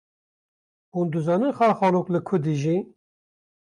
/xɑːlxɑːˈloːk/